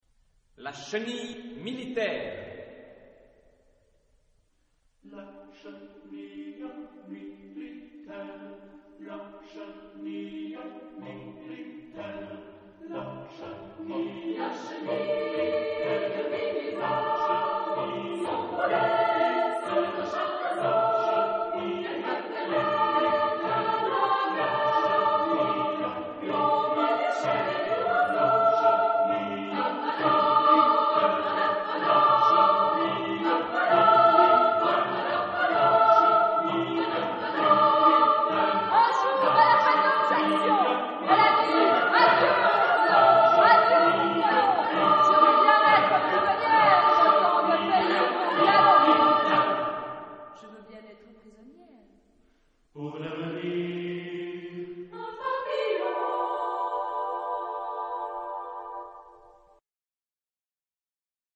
Epoque: 20th century  (1990-2000)
Genre-Style-Form: Choral suite ; Partsong ; Poem ; Secular
Mood of the piece: martial ; lively
Type of Choir: SATB  (4 mixed voices )